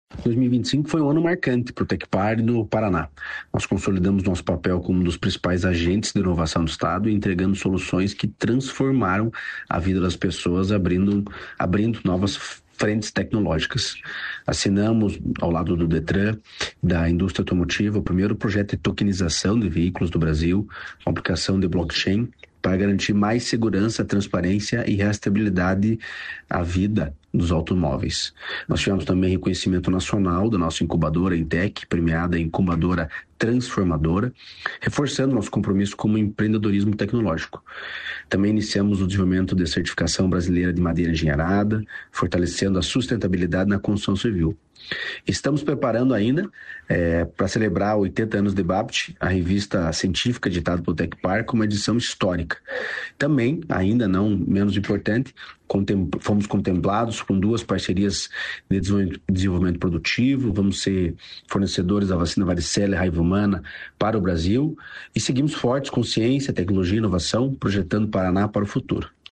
Sonora do diretor-presidente do Tecpar, Eduardo Marafon, sobre fortalecimento no impacto científico e tecnológico no Paraná